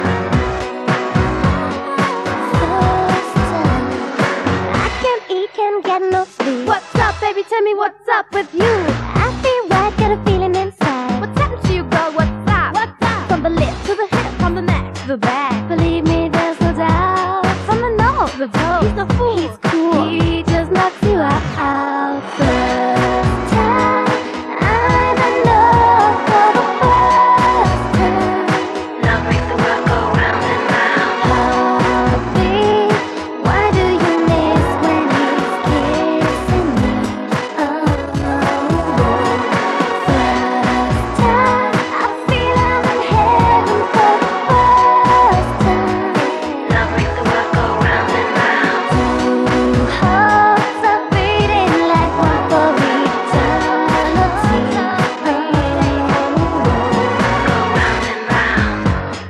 zagraniczna piosenka z kasety